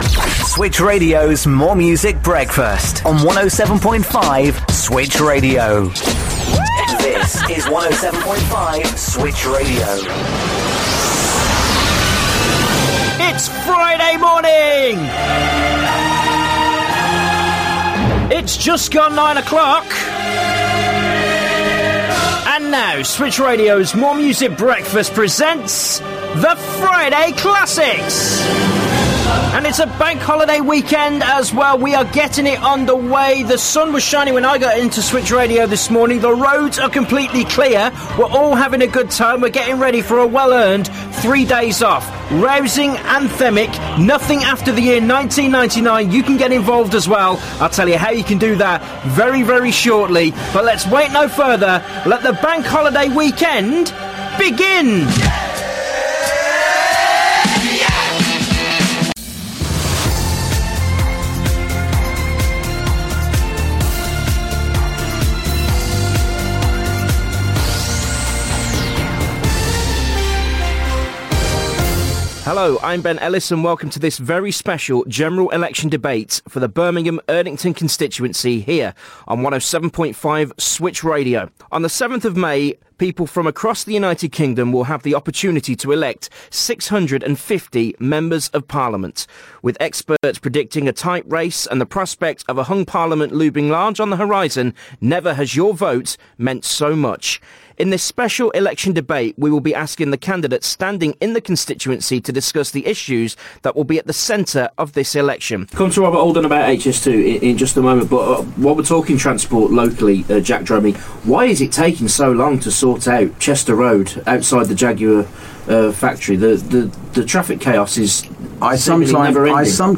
A sample of my best all round radio work.